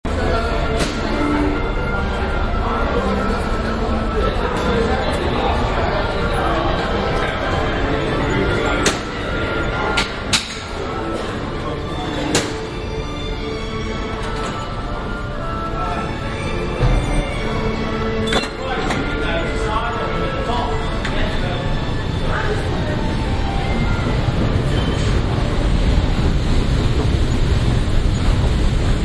Binaural recordings of Central London » Piccadilly Circus Sega machine music, Trocadero
标签： backgroundsound soundscape ambience ambient london ambiance fieldrecording generalnoise atmosphere city
声道立体声